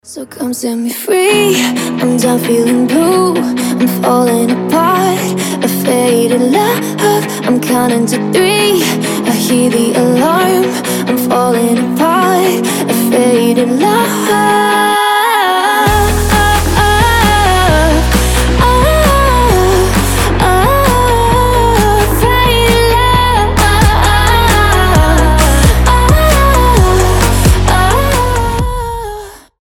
• Качество: 320, Stereo
dance
красивый женский голос